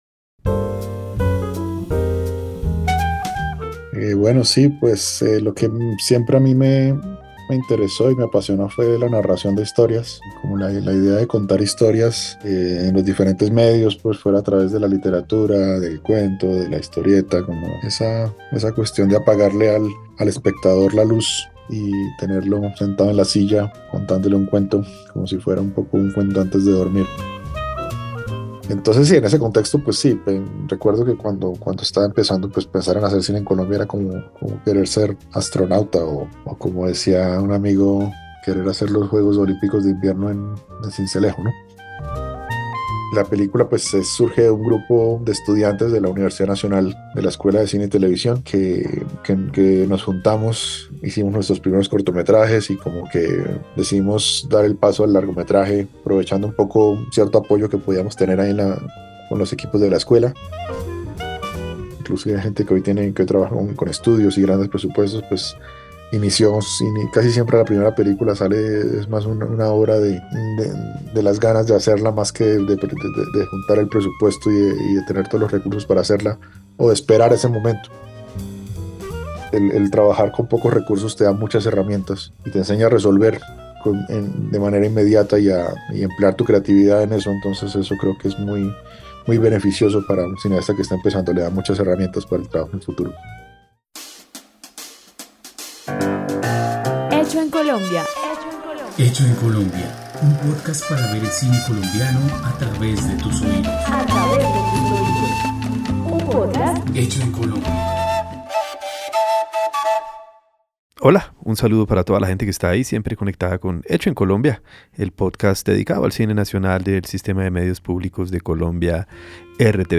..Pódcast. Una conversación con el director Ciro Guerra sobre su ópera prima: La sombra del caminante.
“La sombra del caminante” narra la historia de dos hombres que conectan con la profundidad del ser humano mientras buscan sobrevivir a los fantasmas de la guerra y el exilio. Una charla con el director Ciro Guerra sobre su ópera prima.